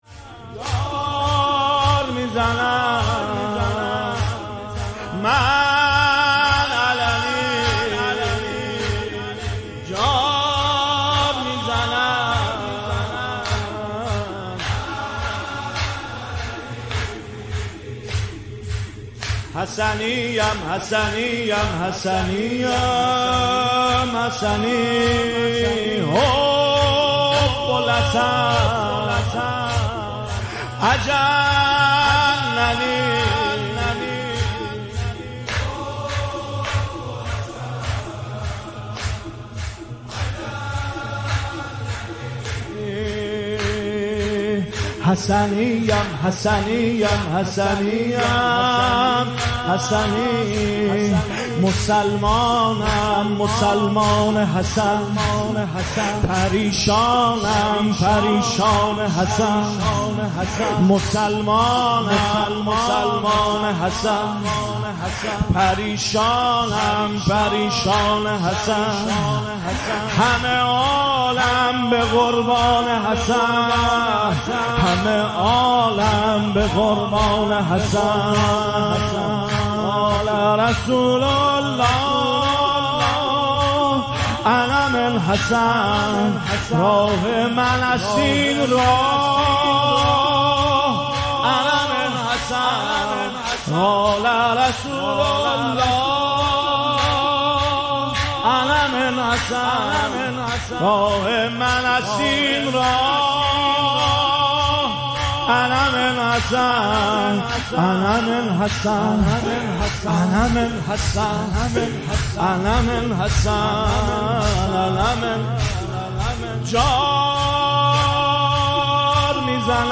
نوحه
مداحی